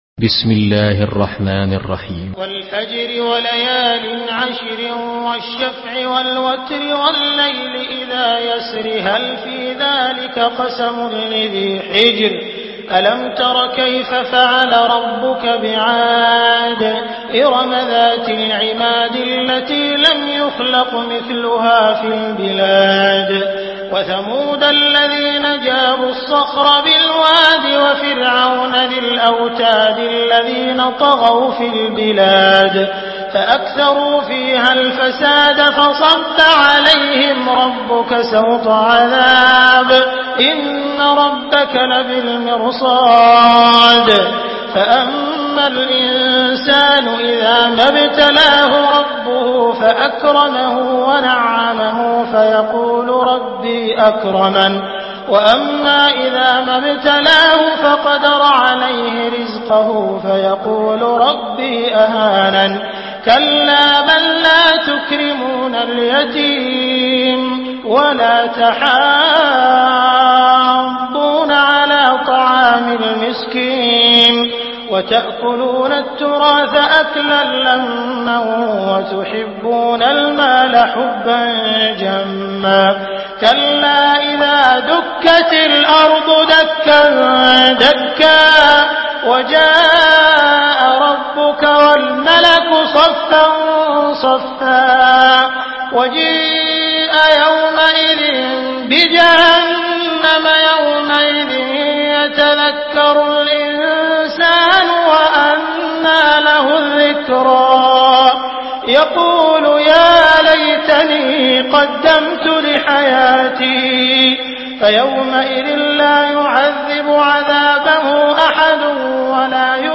سورة الفجر MP3 بصوت عبد الرحمن السديس برواية حفص
مرتل